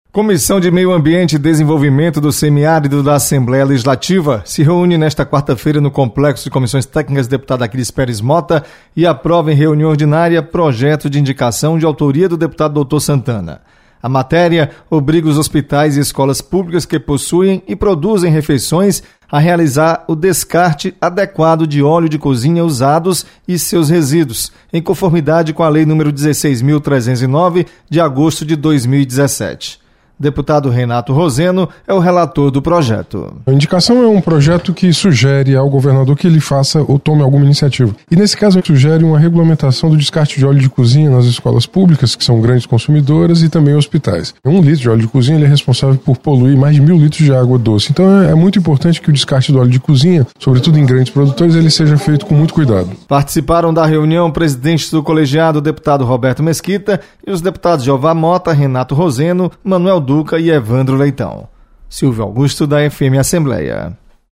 Comissão aprova projeto que obriga descarte adequado de óleo de cozinha . Repórter